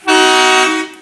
bus_horn.wav